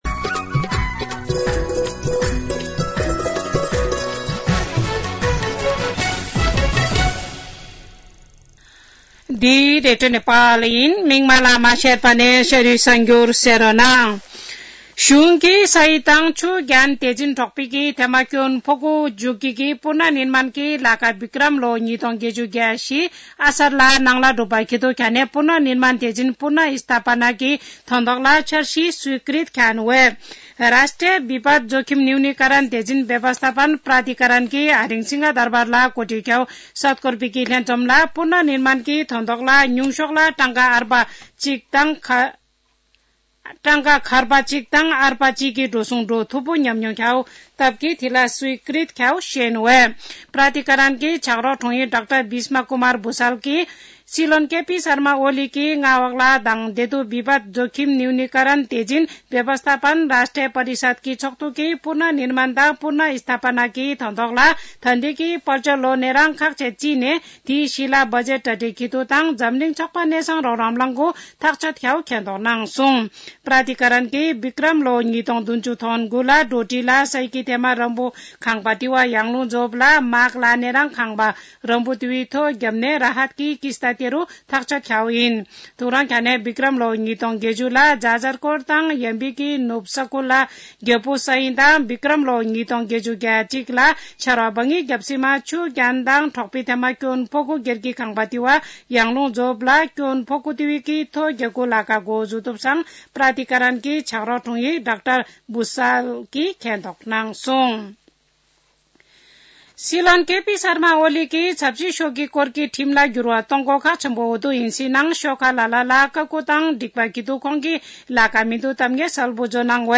शेर्पा भाषाको समाचार : १ माघ , २०८१